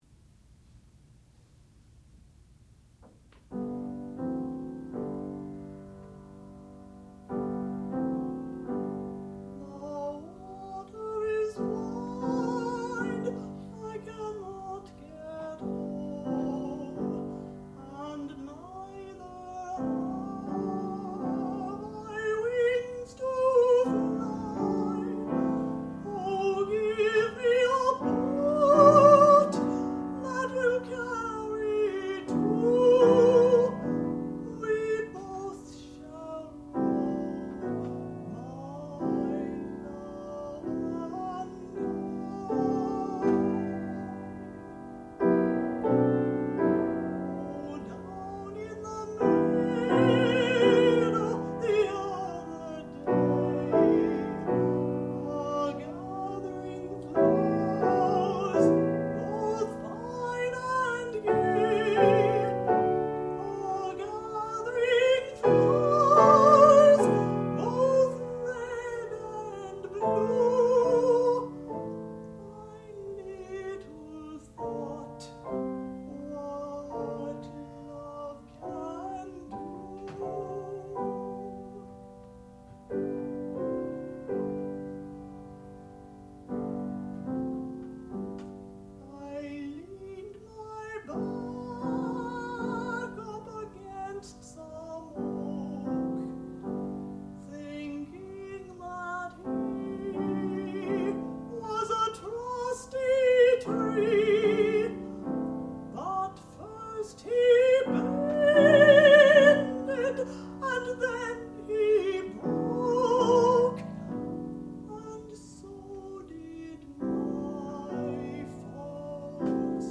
sound recording-musical
classical music
contralto
piano
Master's Degree Recital